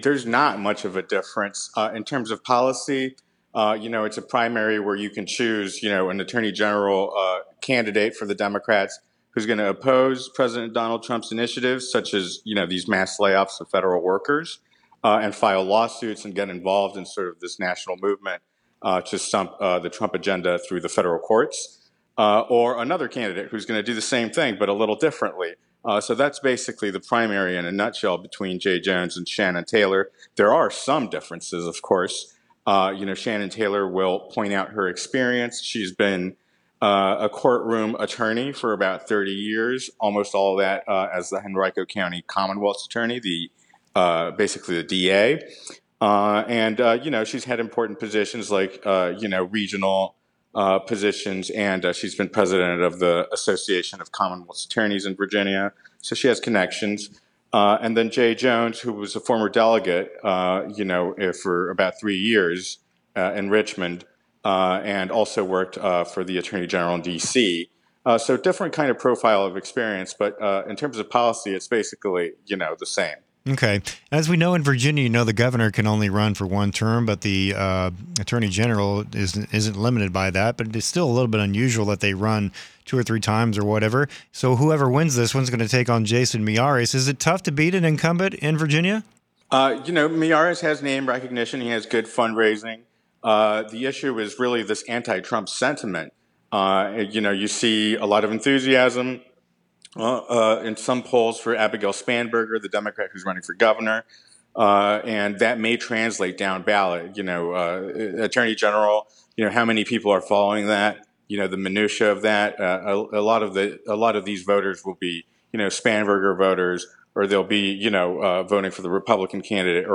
Q&A: Breaking down the Democratic Virginia AG race